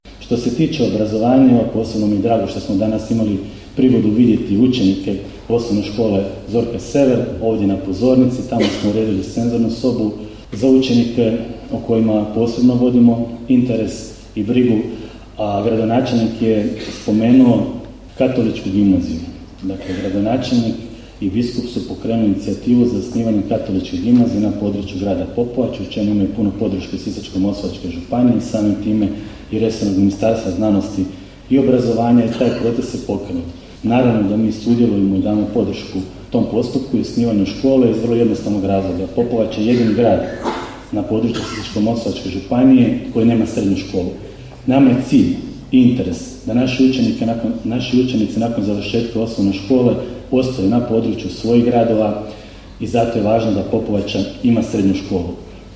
Svečanom sjednicom Gradskog vijeća, Popovača je u srijedu, 21. lipnja 2023. godine proslavila Dan grada i blagdan svoga zaštitnika, sv. Alojzija Gonzage.
Župan se u svom govoru osvrnuo i na obrazovanje na području Popovače